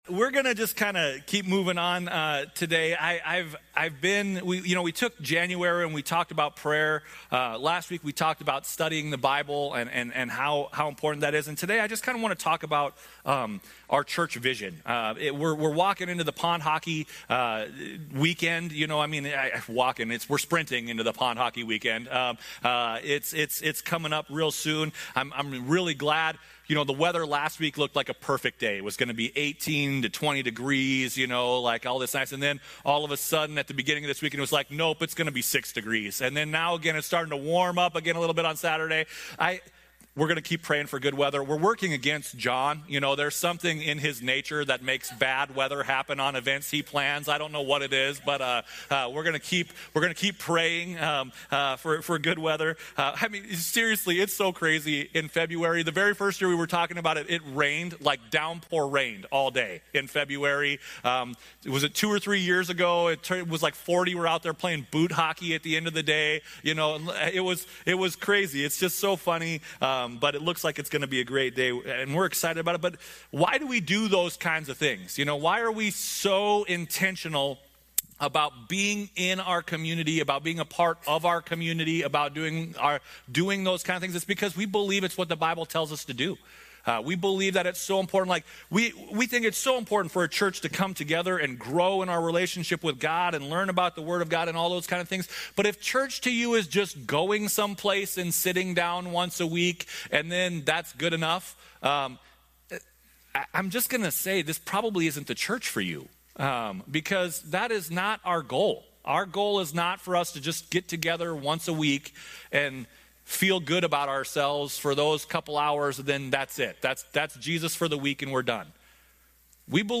A message from the series "Come Home." What a great day as we celebrate the Savior's resurrection on Easter! We start the series Come Home and Baptize two people as well!